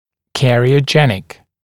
[ˌkærɪə(u)ˈdʒenɪk][ˌкэрио(у)ˈджэник]кариесогенный